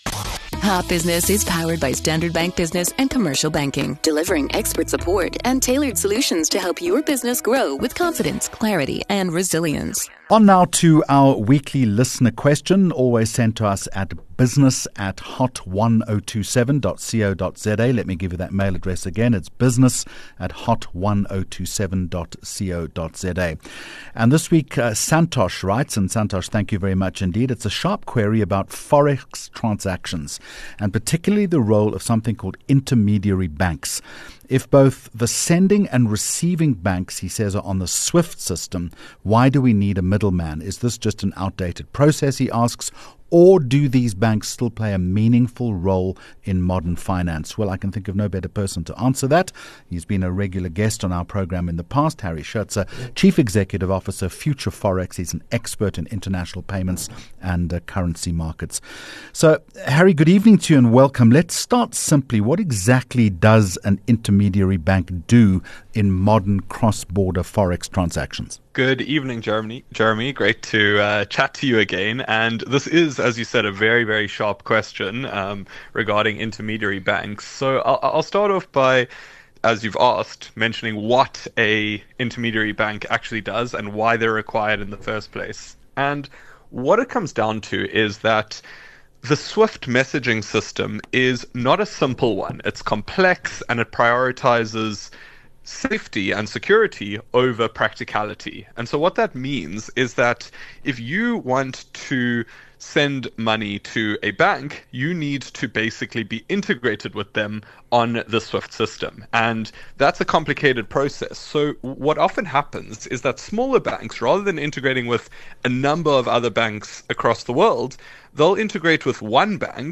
4 Jun Hot Business Interview